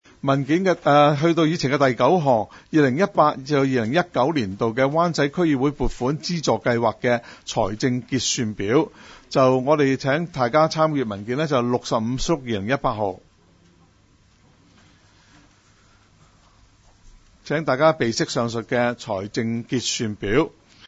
区议会大会的录音记录
湾仔区议会会议室